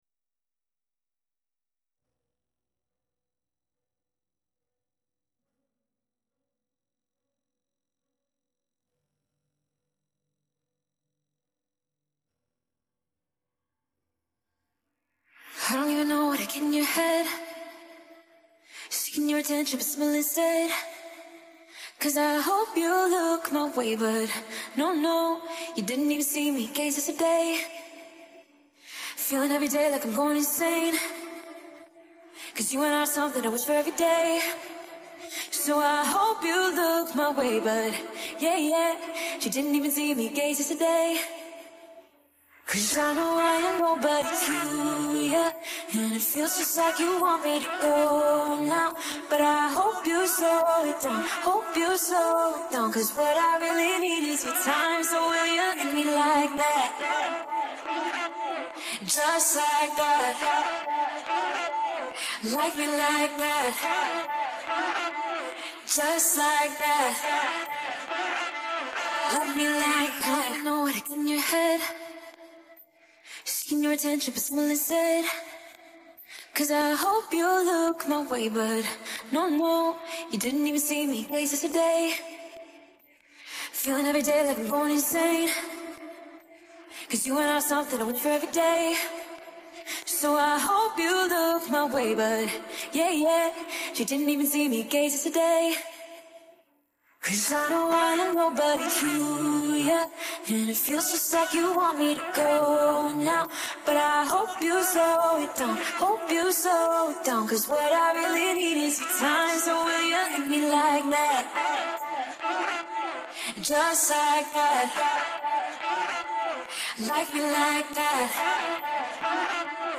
Sangdelen